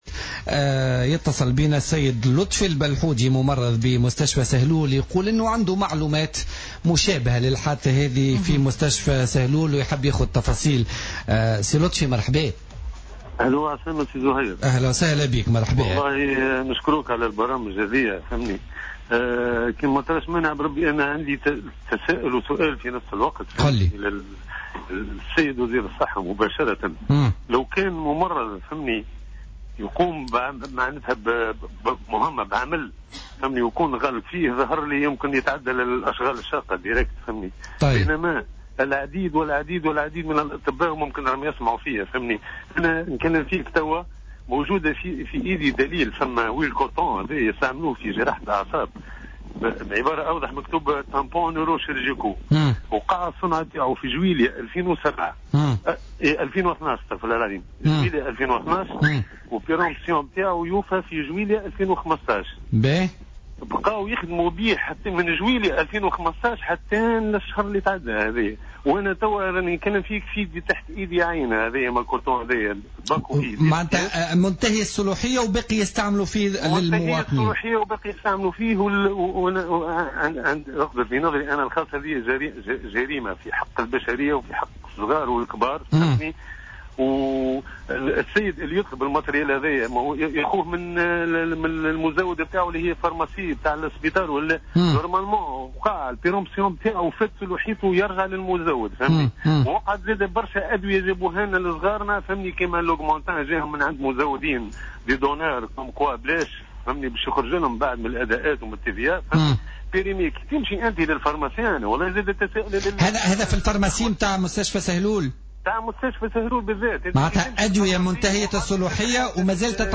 وأضاف في مداخلة له في برنامج "الحدث" اليوم على "الجوهرة أف أم" أنه تم استعمال مستلزم طبي، انتهت مدّة صلاحيته منذ شهر جويلية 2015 .